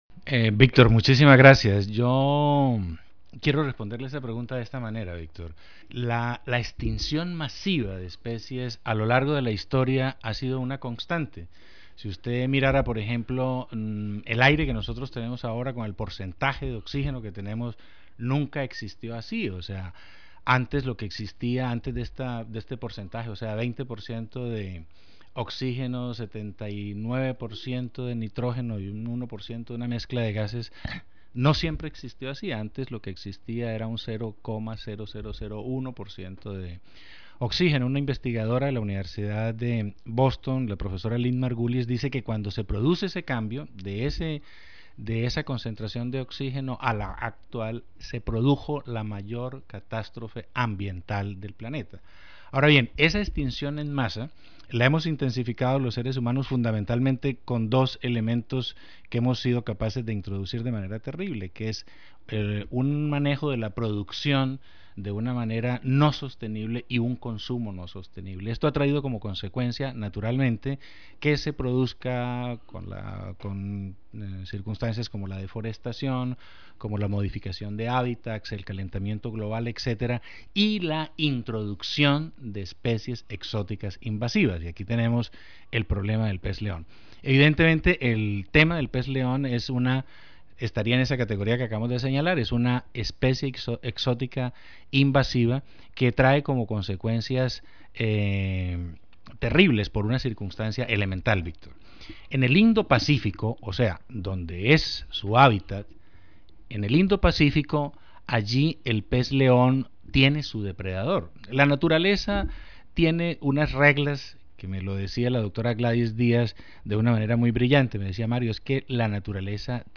Centro del Pensamiento Ambiental del Caribe | Prensa | ¡Alerta! Pez León - Entrevista